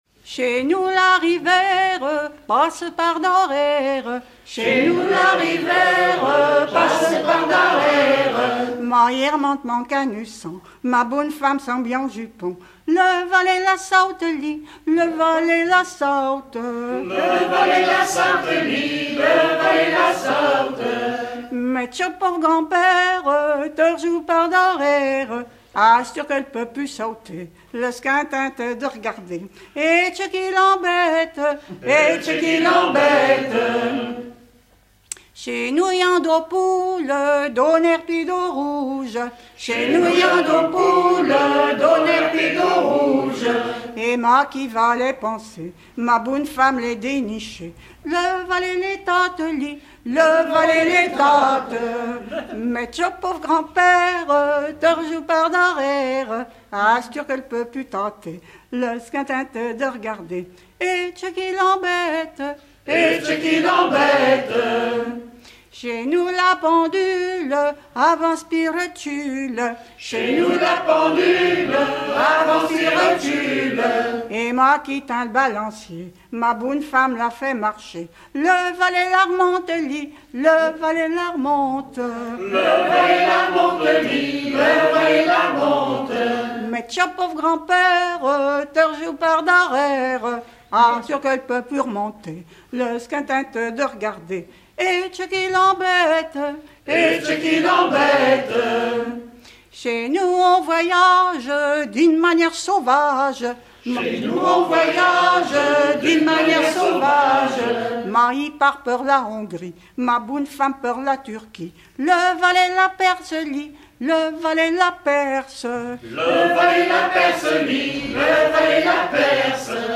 Genre énumérative
Pièce musicale éditée